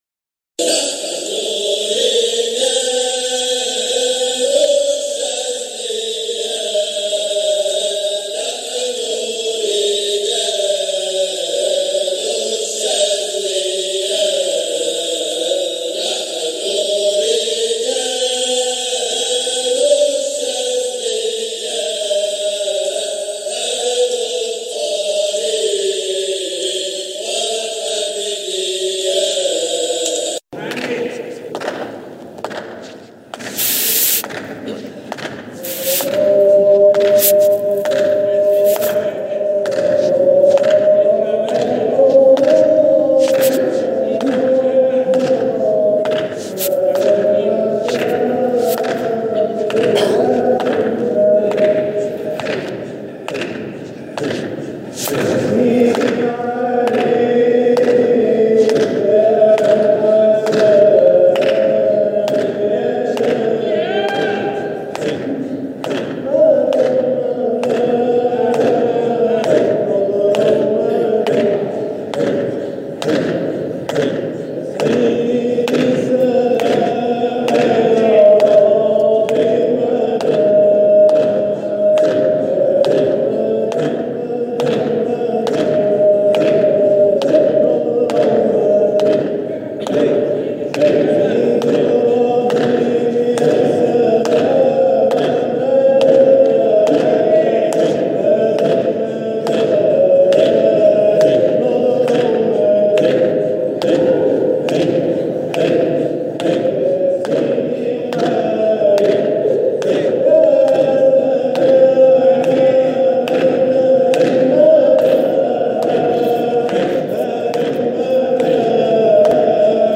مقاطع من احتفالات ابناء الطريقة الحامدية الشاذلية بمناسباتهم
جزء من حلقة ذكر بمسجد سيدنا ابى الحسن الشاذلى قدس سره